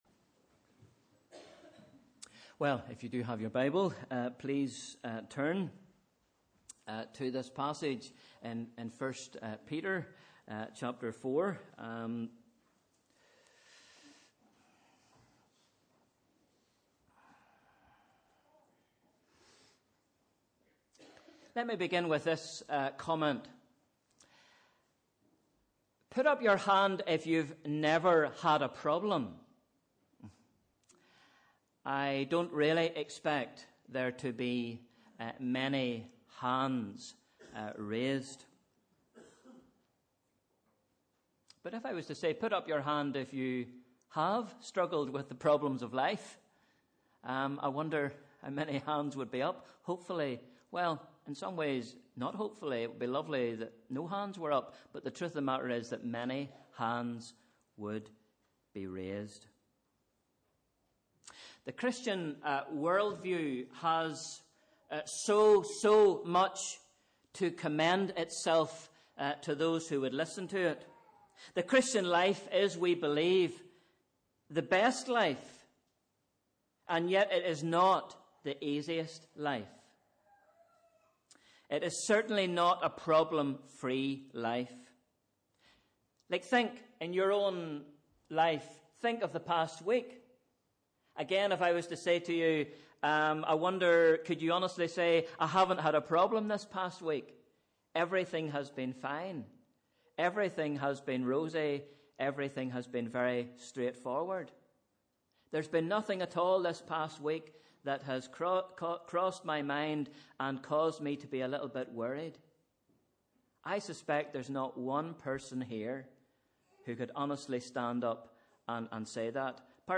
Sunday 26th July – Morning Service @ 11:00am